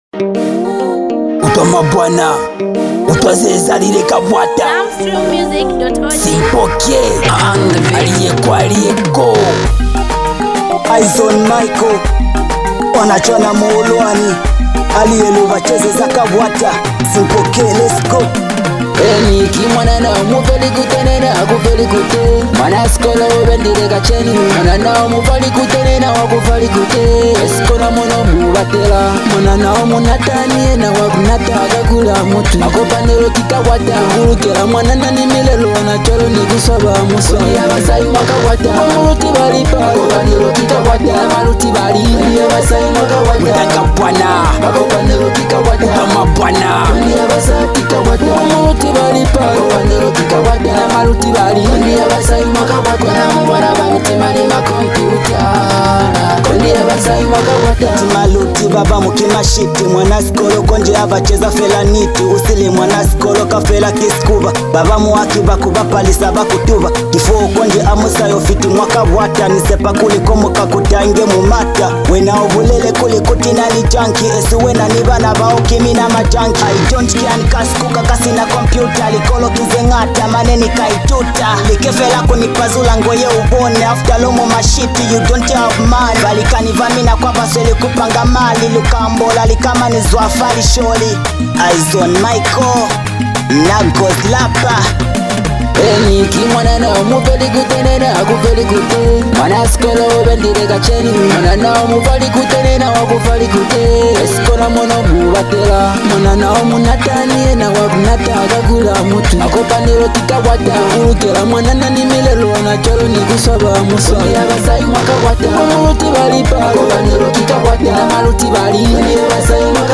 With smooth melodies, relatable lyrics, and rich production
Afro-inspired sounds with local influence